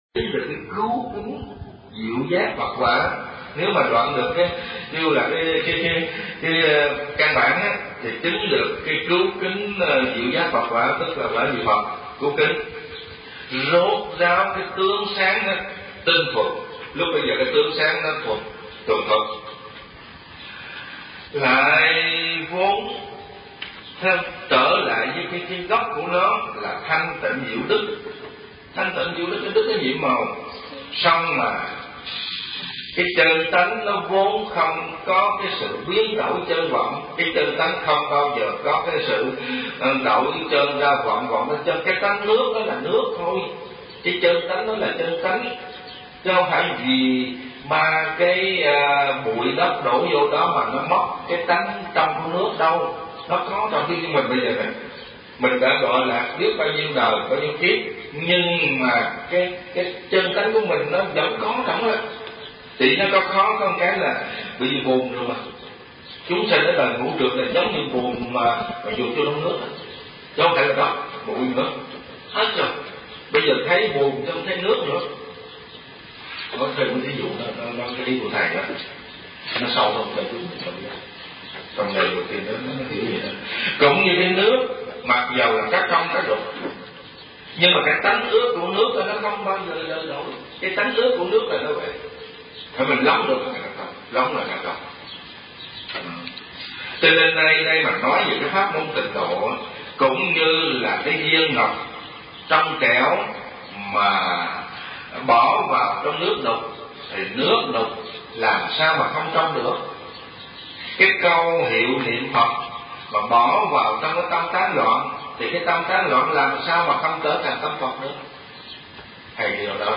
Kinh Giảng Nhị Khóa Hiệp Giải